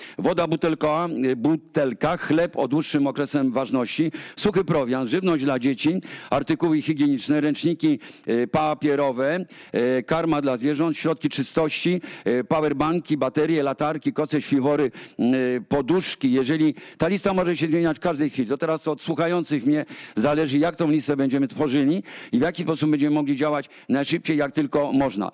Szef WOŚP przeczytał też listę sporządzoną przez samorządowców z miejscowości zniszczonych powodzią. Są na niej artykuły, których w tym momencie najbardziej potrzebują.